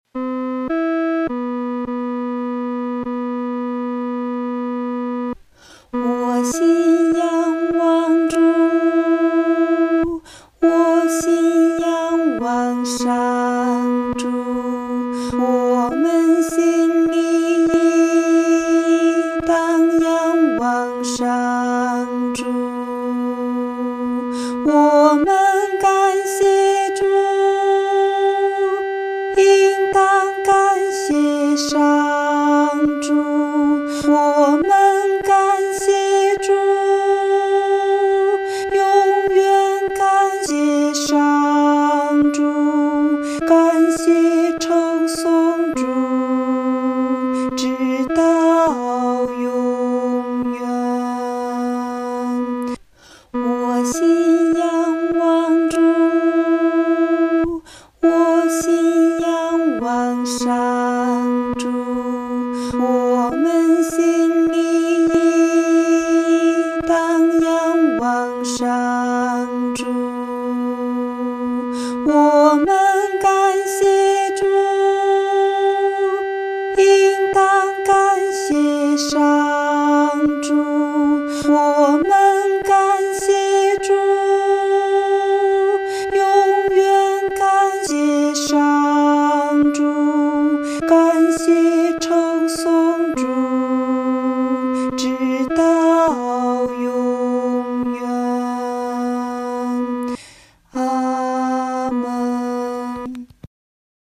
女低
本首圣诗由网上圣诗班 (石家庄二）录制